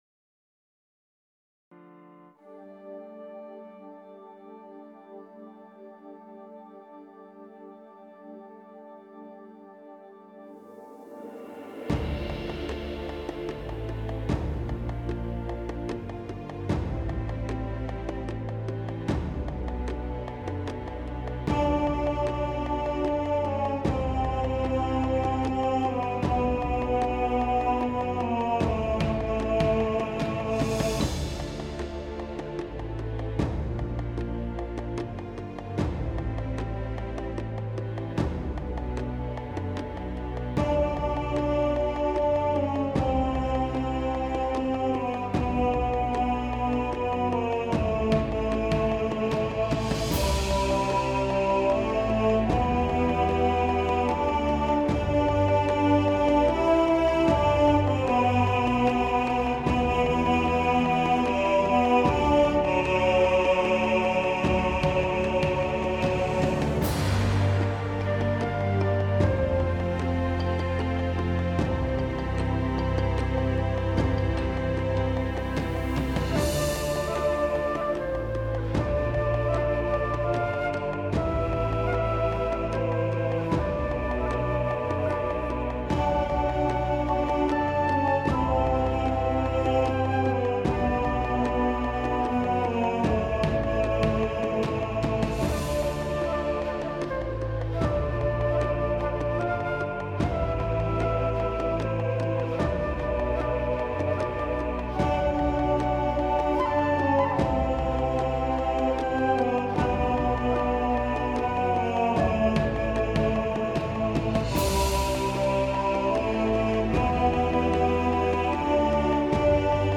Adiemus – Bass | Ipswich Hospital Community Choir